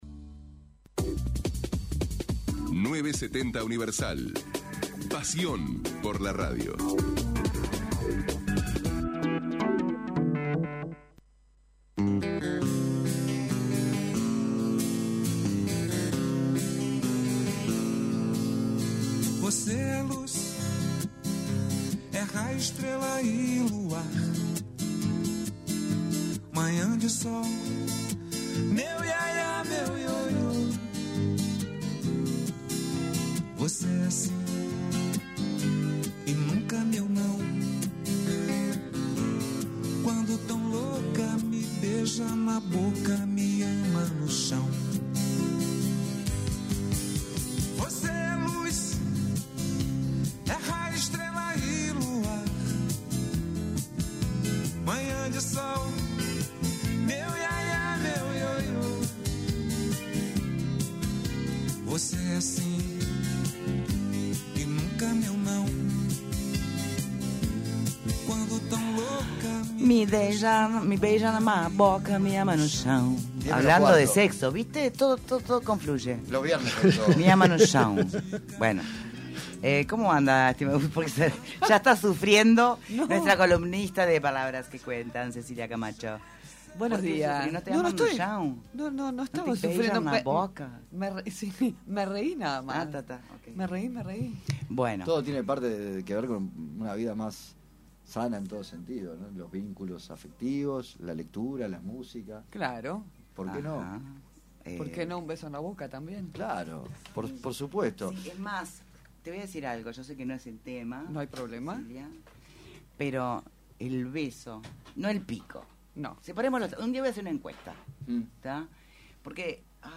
Escuche la columna completa aquí: En una nueva entrega de “Palabras que cuentan”